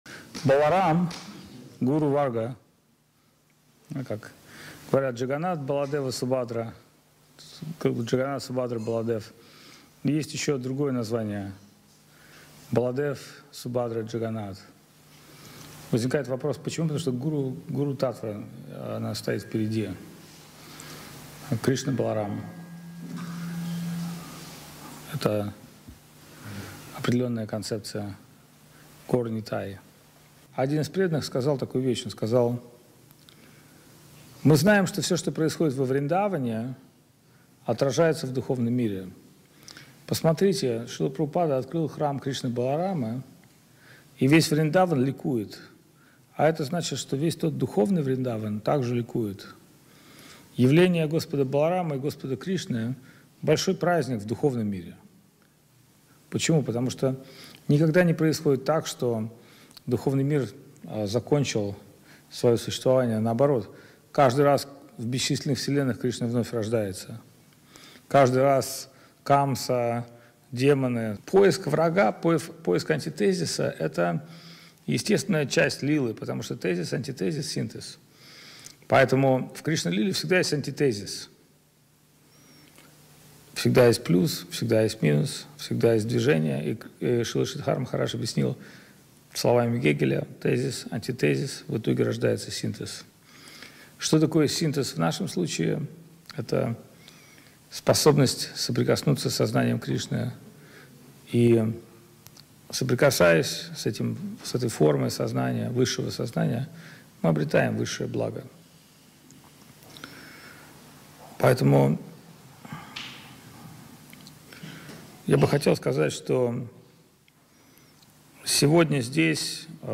Лахта, Санкт-Петербург
Праздник 12-летия со дня установки Божеств Шри Шри Гуру-Гауранги Радхи-Мадхавы в Лахте, Санкт Петербург 00:00:00 Великий праздник в духовном мире